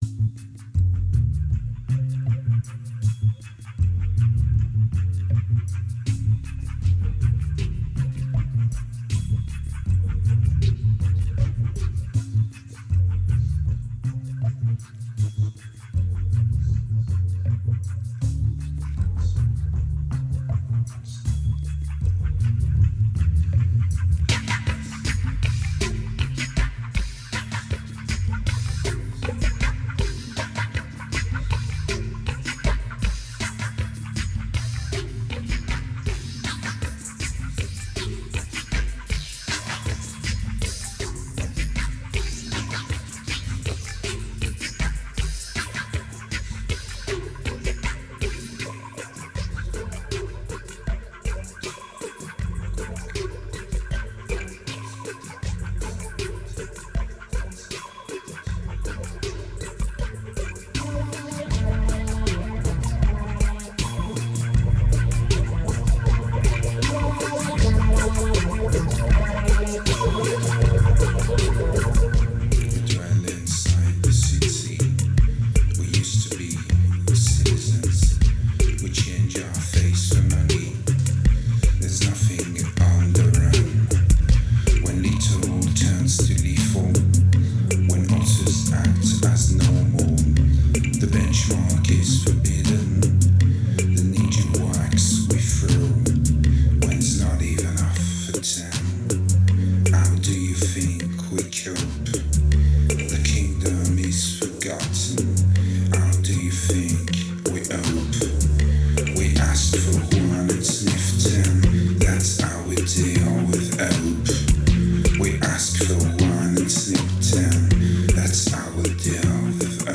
2196📈 - -54%🤔 - 94BPM🔊 - 2009-04-28📅 - -406🌟
Interlude Drums Useless Section Break Bare Questions